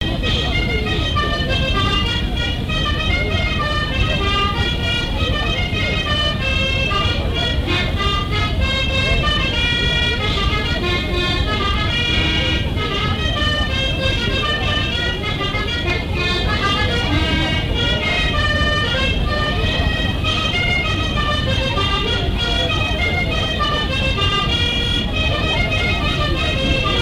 danse : scottich trois pas
lors d'une kermesse
Pièce musicale inédite